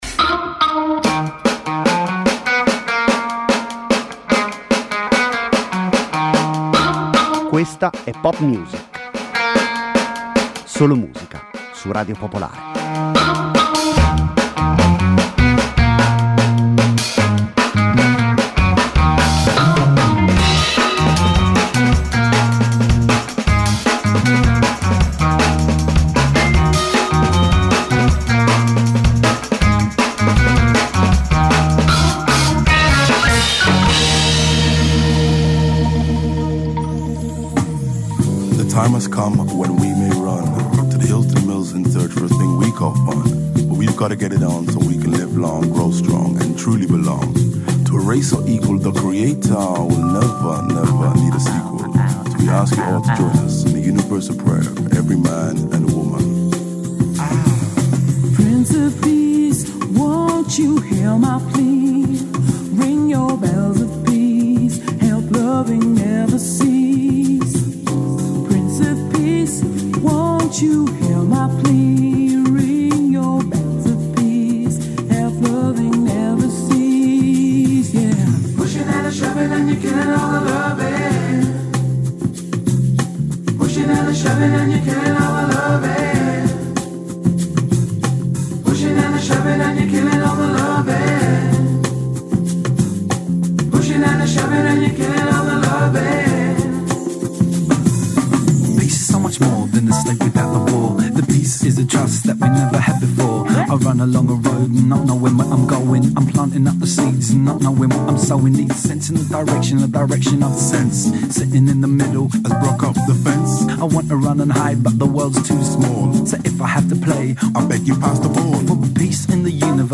Una trasmissione di musica, senza confini e senza barriere.
Senza conduttori, senza didascalie: solo e soltanto musica.